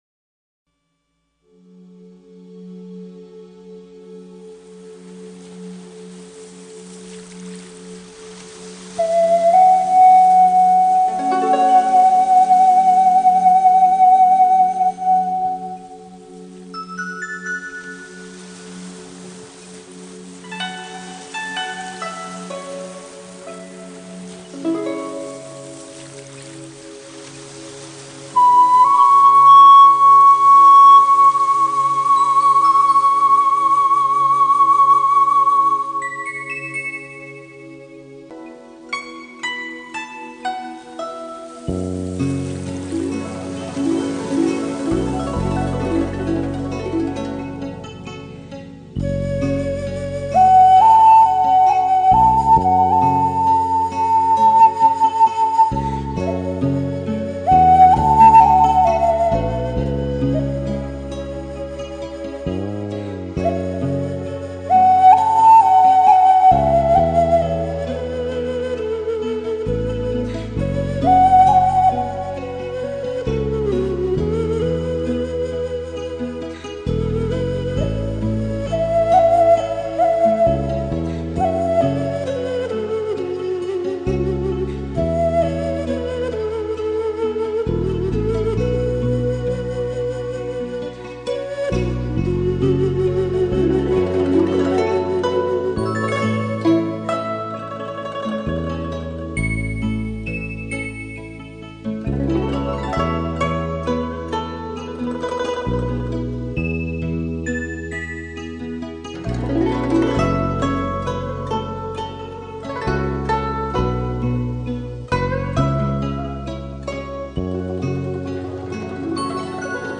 空灵悠远，来自东方遥远的回忆，带给你心灵的慰籍。